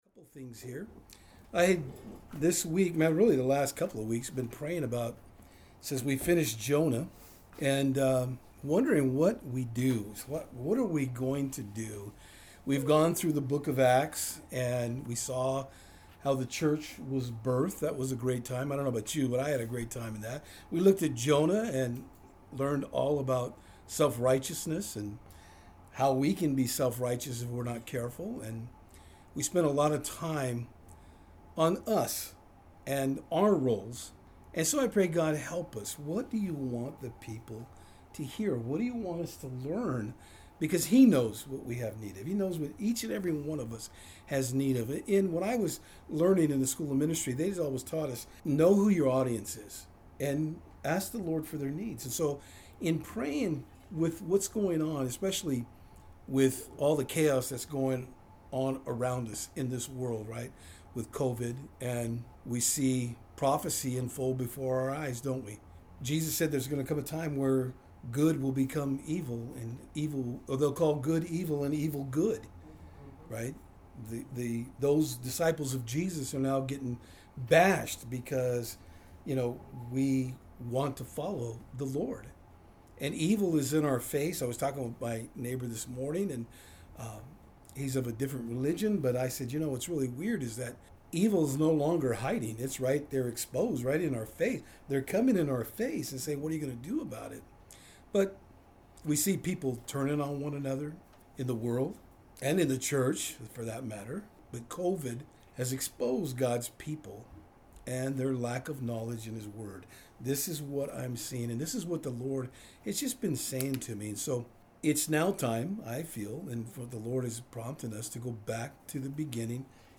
Genesis 1:1-2 Service Type: Saturdays on Fort Hill Today we begin at the “Beginning.”